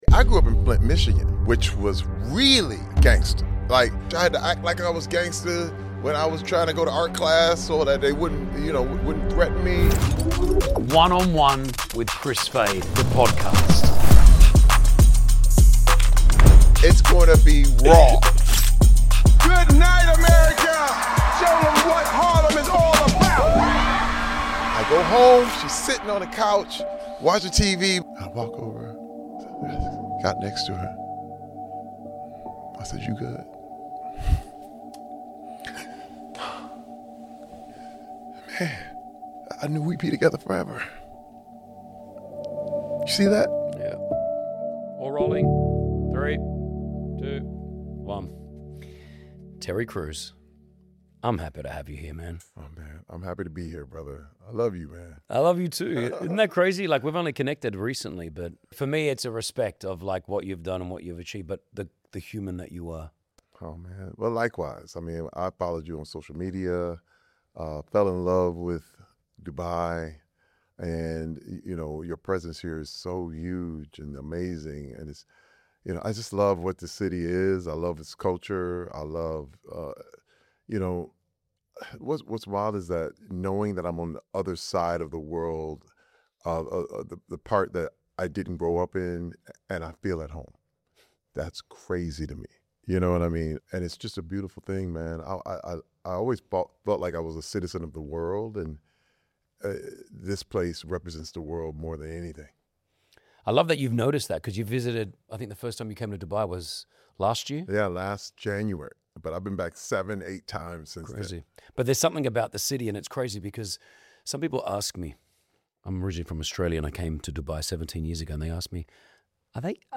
Terry Crews sits down with Kris Fade for the first episode of One on One — and holds nothing back. From sleeping on the floors of Hollywood to becoming one of the most recognizable faces on American television, Terry opens up about what it actually takes to reinvent yourself multiple times over.